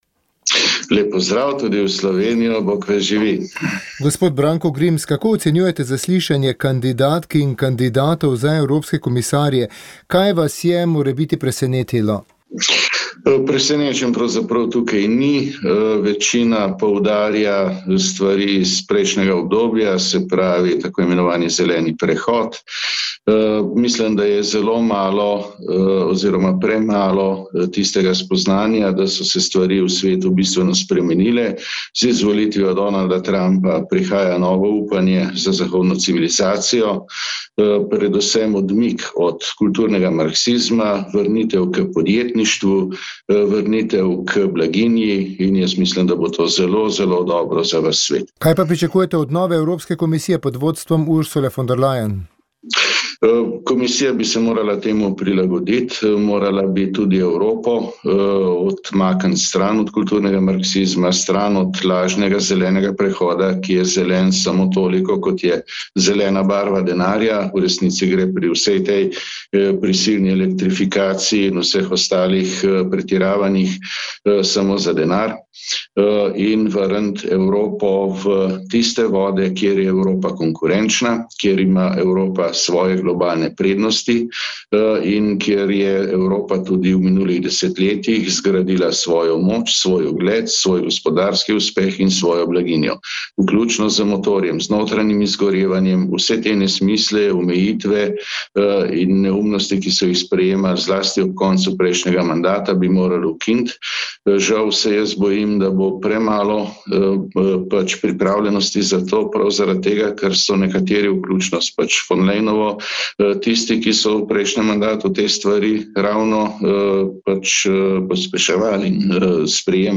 Slovenski poslanec Branko Grims je v pogovoru za Radio Ognjišče podal oster pogled na zaslišanja kandidatov za evropske komisarje ter prihodnost Evropske unije pod vodstvom Ursule von der Leyen. Njegova analiza je temeljila na oceni trenutnih izzivov, predvsem tistih, povezanih z zelenim prehodom, kulturnim marksizmom in vprašanji evropske identitete.